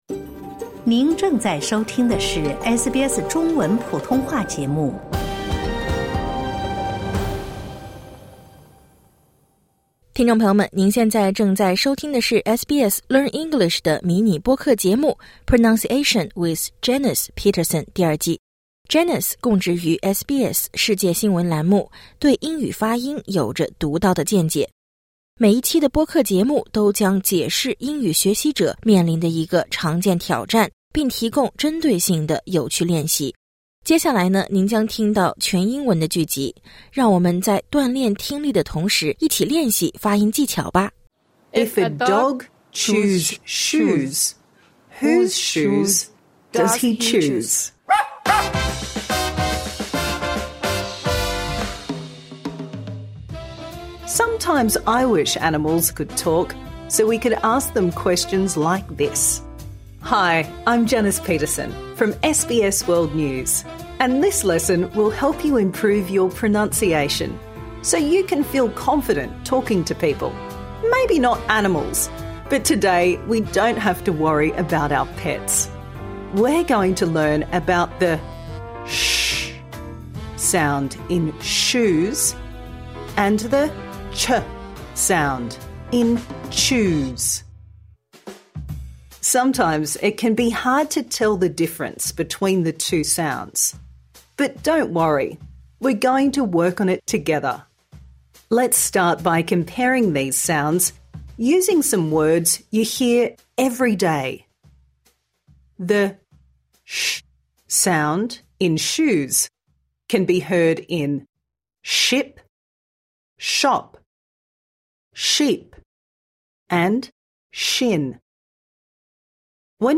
Improve your pronunciation | Season 2
Minimal Pairs: /tʃ/ chew, chip, chop, cheap, chin /ʃ/ shoe, ship, shop, sheep, shin SBS Learn English will help you speak, understand and connect in Australia.